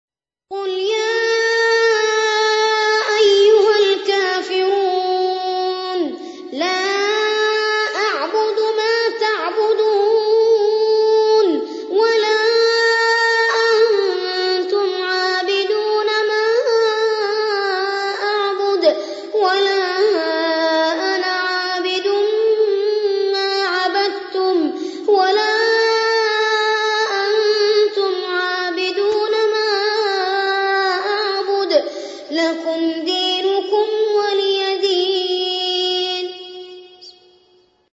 قارئ معتمد رواية ورش عن نافع
أحد أشهر قراء القرآن الكريم في العالم الإسلامي، يتميز بجمال صوته وقوة نفسه وإتقانه للمقامات الموسيقية في التلاوة.